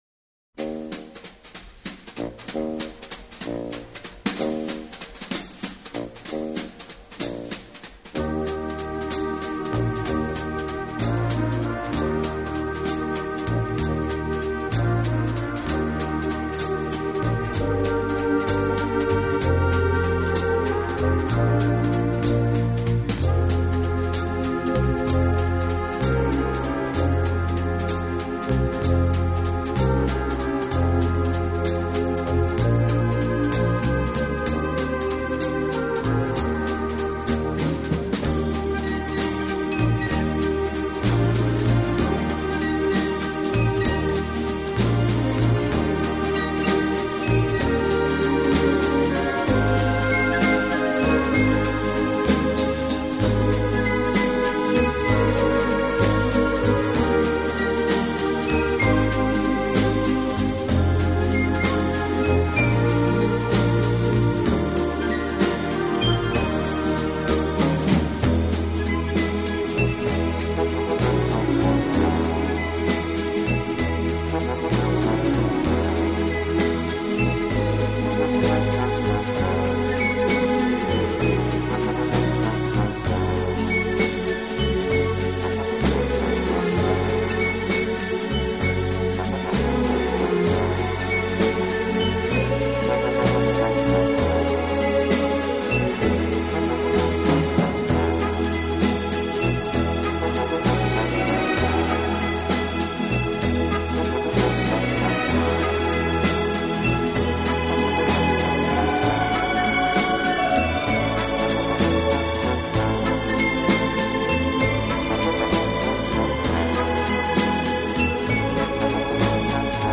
【轻音乐】
【顶级轻音乐】